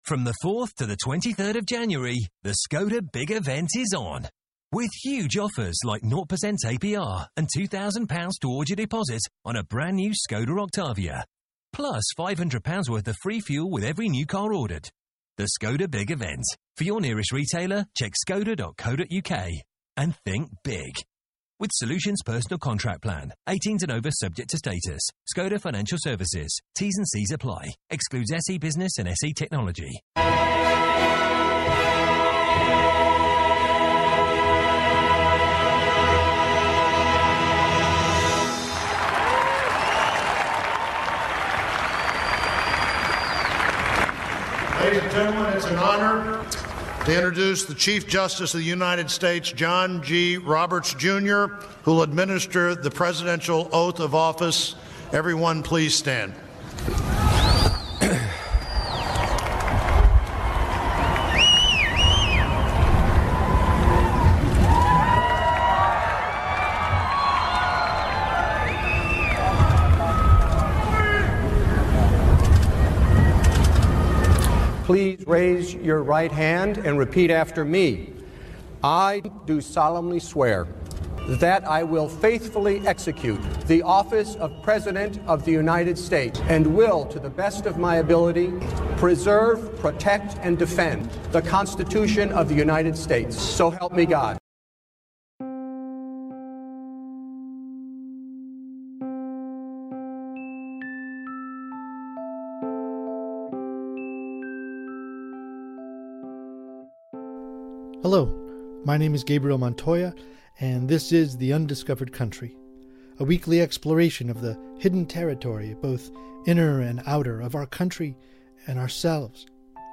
Episode 1 is an interview